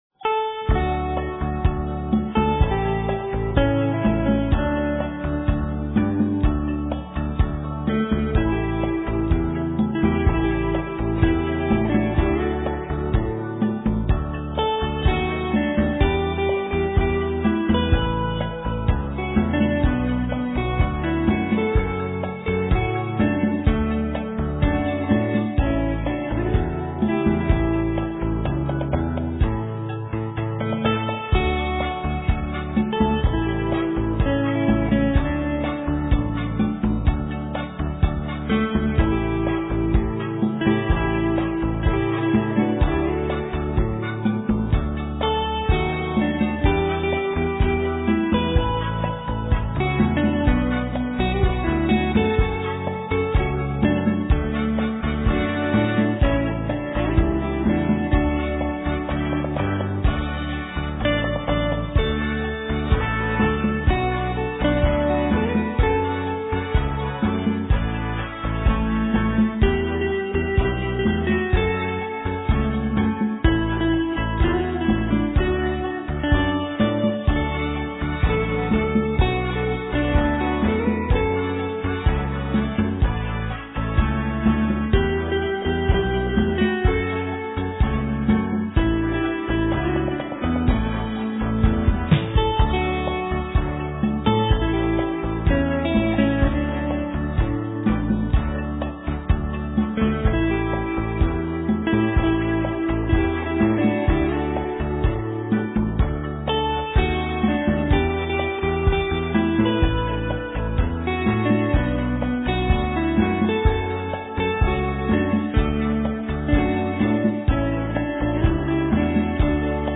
* Ca sĩ: Không lời
* Thể loại: Việt Nam